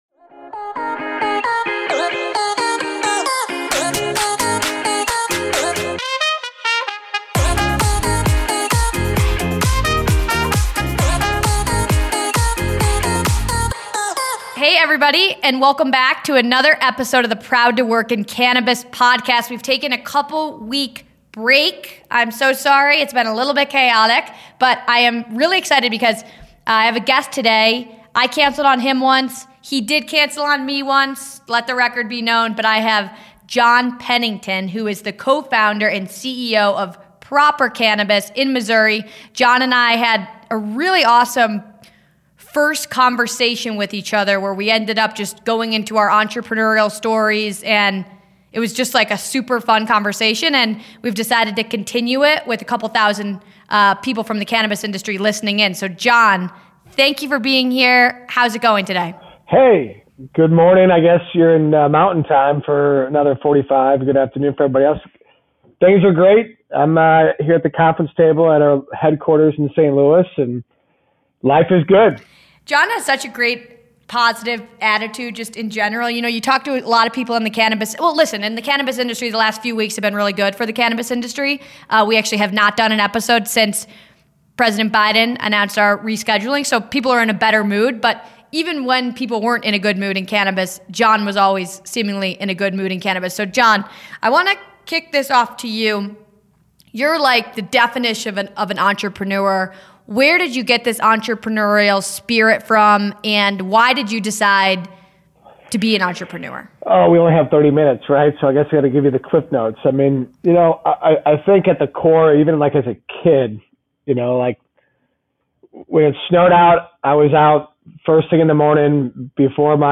for conversations with the people behind the cannabis industry.
interview cannabis industry employees ranging from entry level to executive